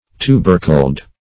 Search Result for " tubercled" : The Collaborative International Dictionary of English v.0.48: Tubercled \Tu"ber*cled\, a. Having tubercles; affected with, tubercles; tuberculate; as, a tubercled lung or stalk.